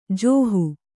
♪ jōhu